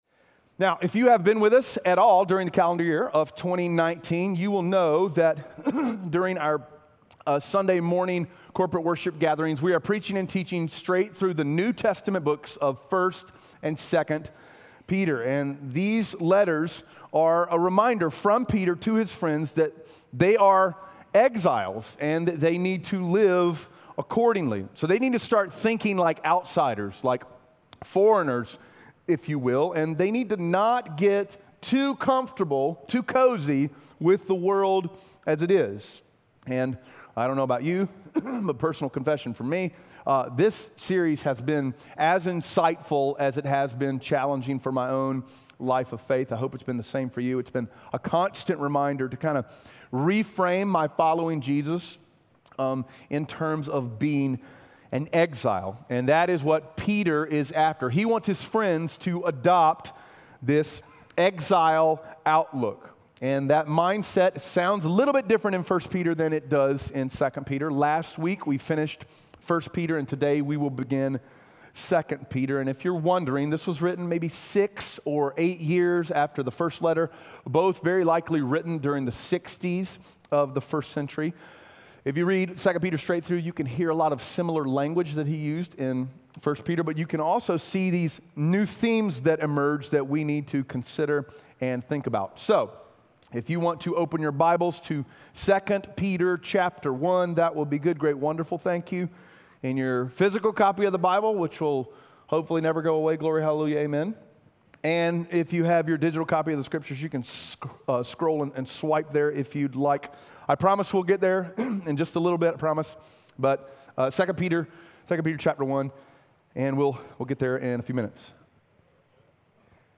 2 Peter 1:1-15 Audio Sermon Notes (PDF) Ask a Question Identity is an ever-present buzzword.